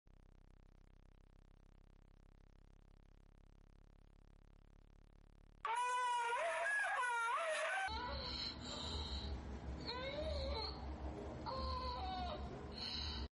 Speaker Cleaner Sound Water & Sound Effects Free Download